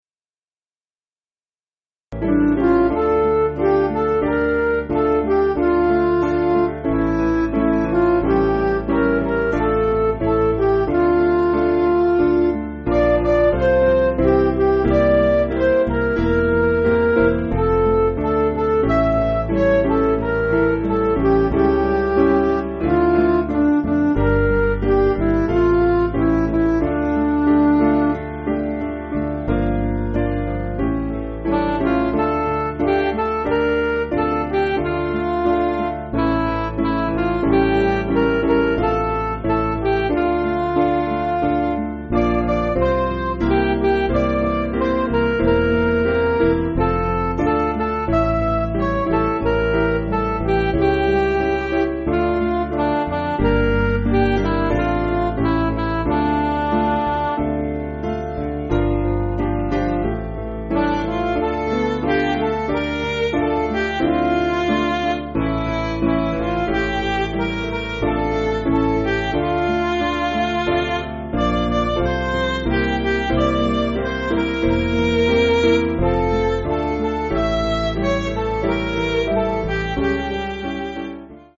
Piano & Instrumental
(CM)   3/Dm